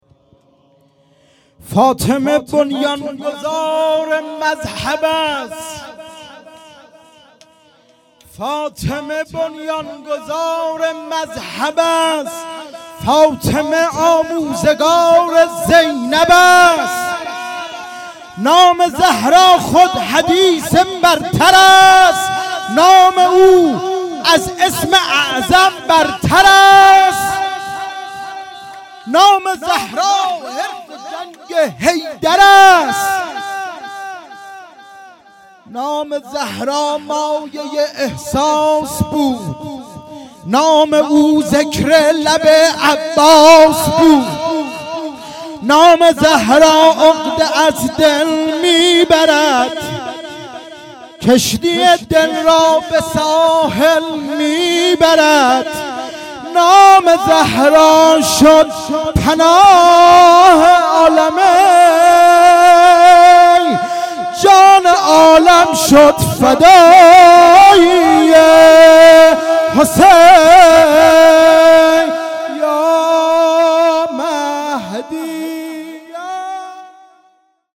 شعر خوانی در وصف حضرت صدیقه س